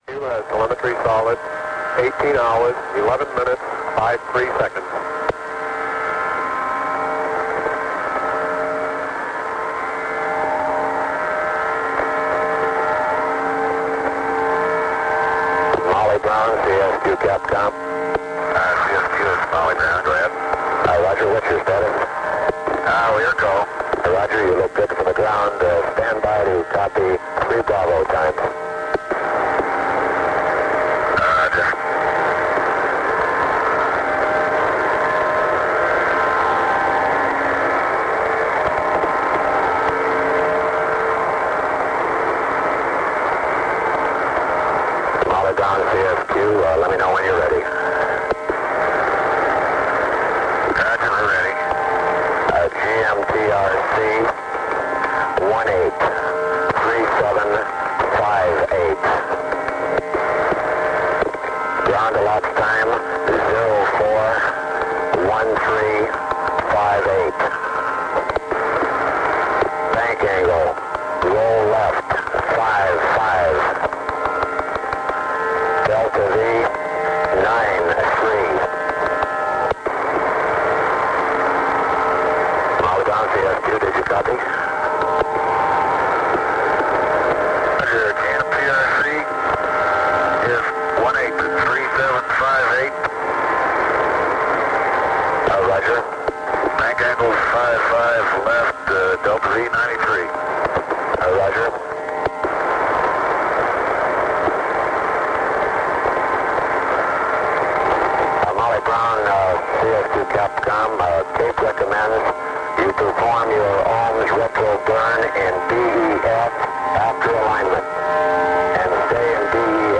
Recording starts at acquisition. The audio quality is poor because CSQ (and RKV, for that matter) are linked into the Network via HF radio.
Recorded at Carnarvon.